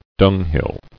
[dung·hill]